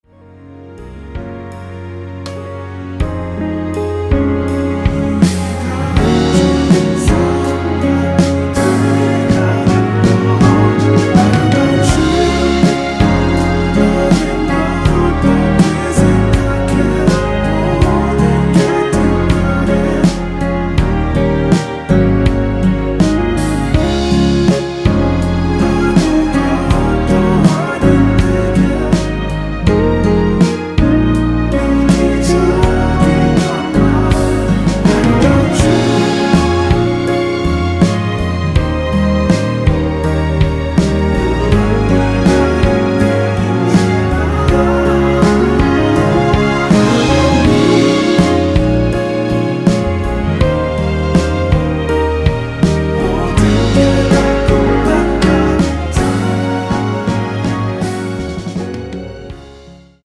원키에서(-1)내린 코러스 포함된 MR입니다.(미리듣기 확인)
Db
앞부분30초, 뒷부분30초씩 편집해서 올려 드리고 있습니다.
중간에 음이 끈어지고 다시 나오는 이유는